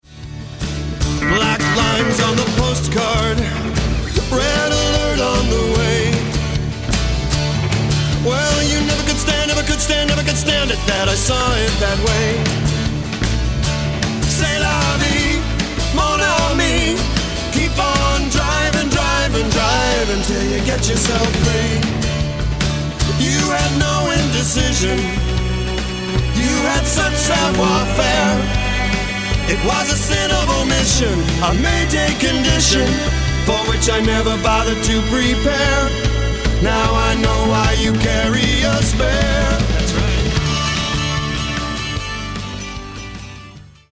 Acoustic & Electric Guitars, Vocals
Acoustic Guitars, Keyboards, Percussion, Vocals